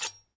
刀片振动和故障 " Blade8
描述：记录的刀片声音。
Tag: 刀片声 单击 打击乐器 录音 毛刺 叶片 振动 现场录音 拍摄 声音